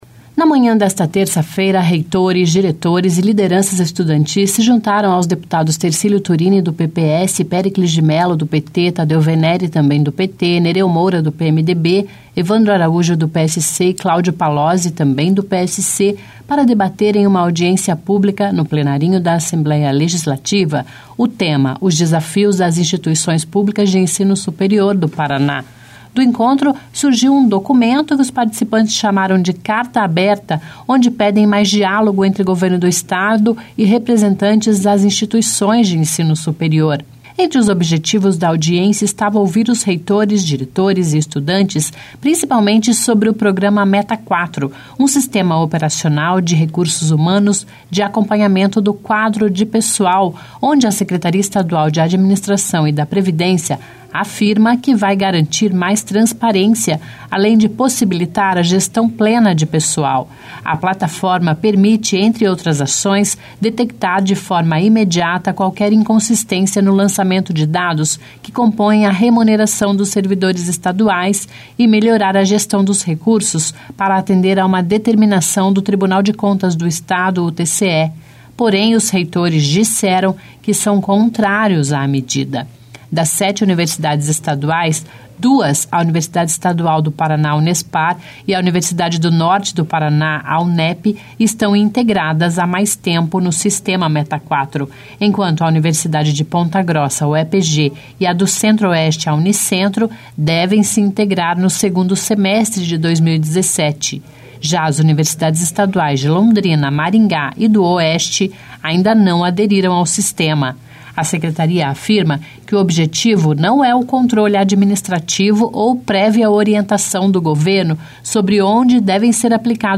Em audiência, lideranças e deputados da oposição se opõem ao Sistema Meta4